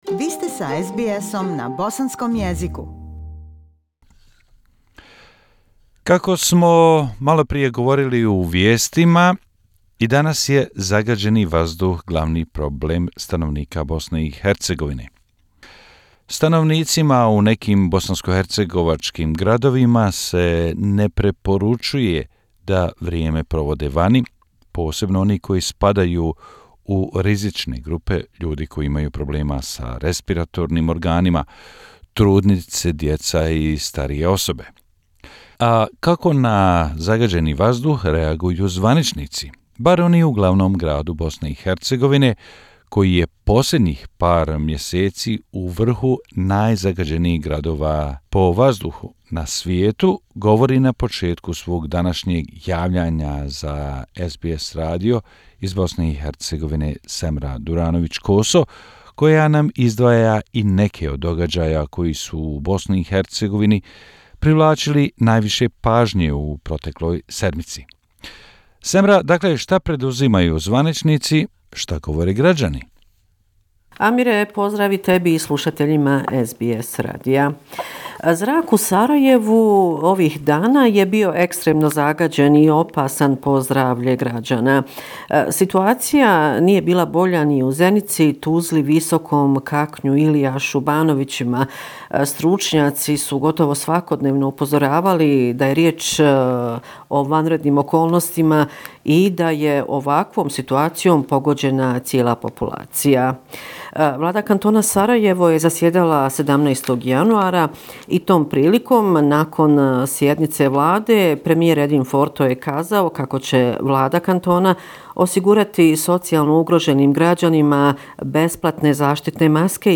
Bosnia and Herzegovina - affairs in the country for the last seven day, weekly report January 19, 202O